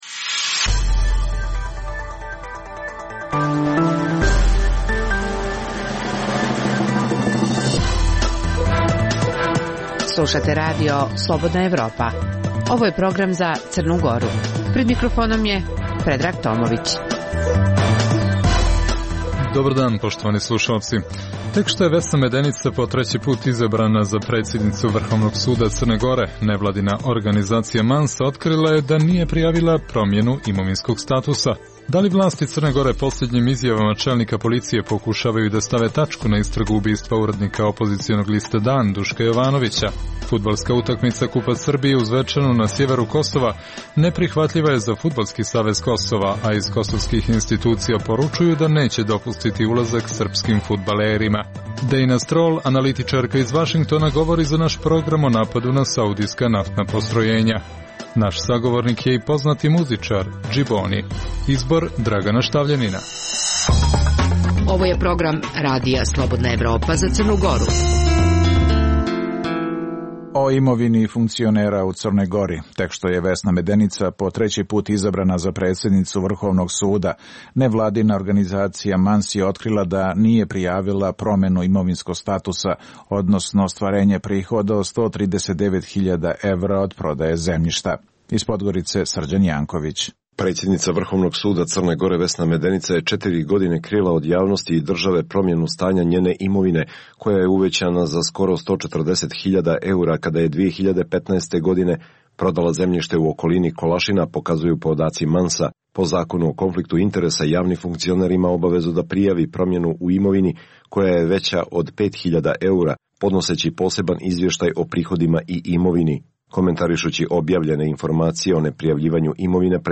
Emisija namijenjena slušaocima u Crnoj Gori. Sadrži lokalne, regionalne i vijesti iz svijeta, rezime sedmice, tematske priloge o aktuelnim dešavanjima u Crnoj Gori i temu iz regiona.